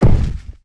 gibwood.wav